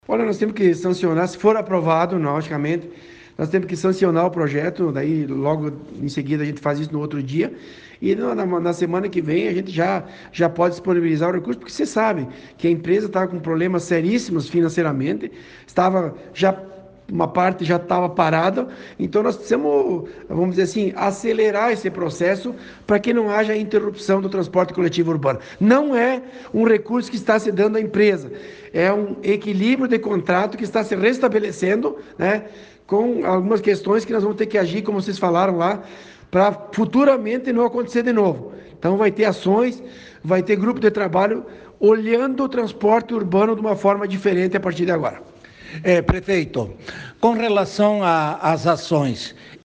Em entrevista ao vivo a Rádio Cultura, o prefeito disse que a oportunidade serviu para apresentar o projeto e explicar porque se a situação da Empresa de Transportes Gaurama chegou a este ponto.
Ouça o que disse Polis em entrevista a Rádio Cultura